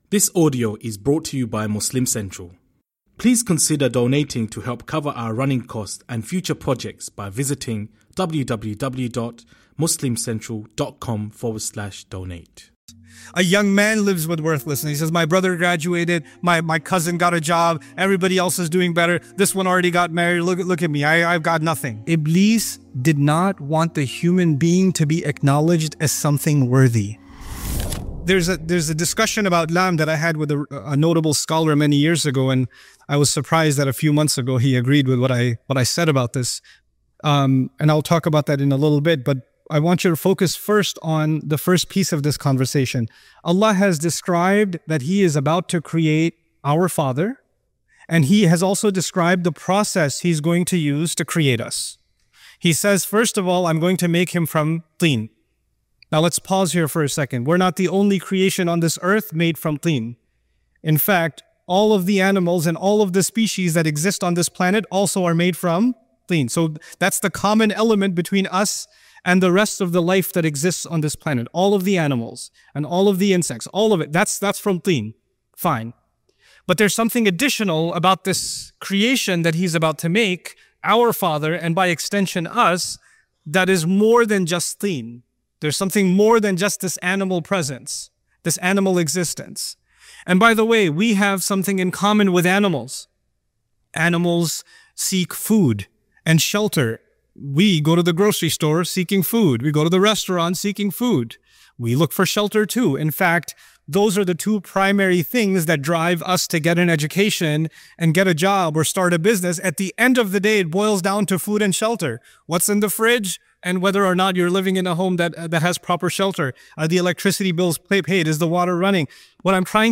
You Are Valuable - This Is How Allah Created You - Khutbah Highlights -